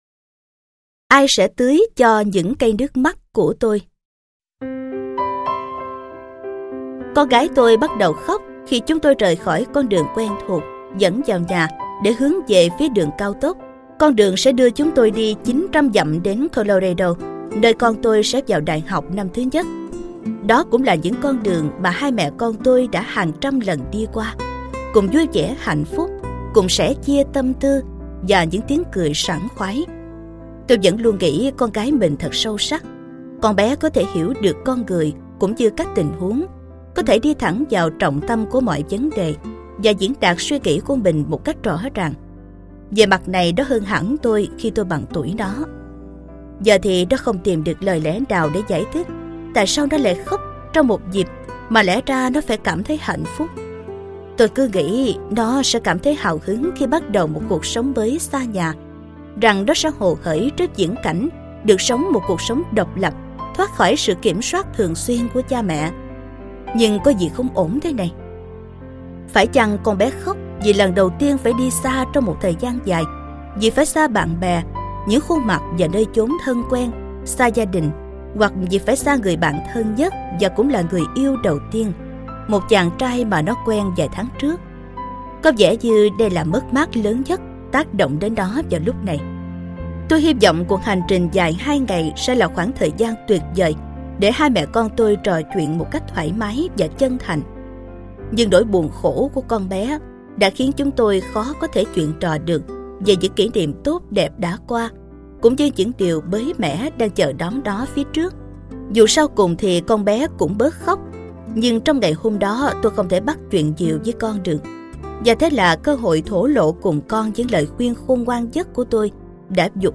Sách nói Chicken Soup 9 - Vòng Tay Của Mẹ - Jack Canfield - Sách Nói Online Hay